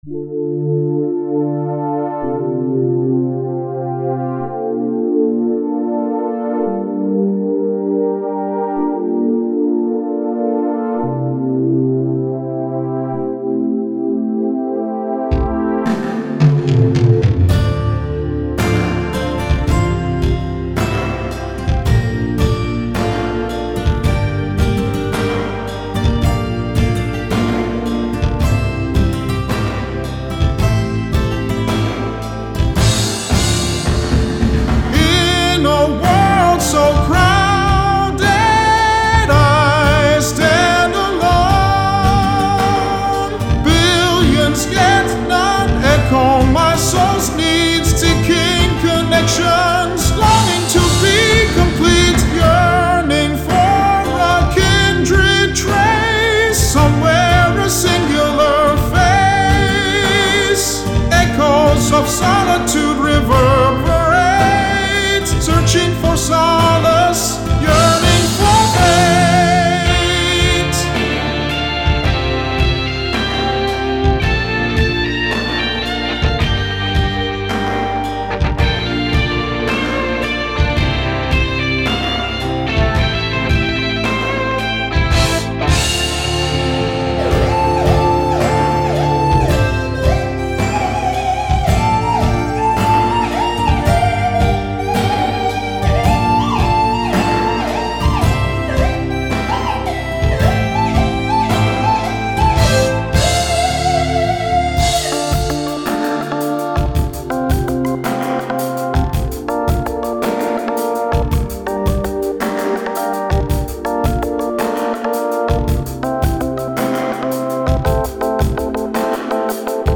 Genre: Ballad.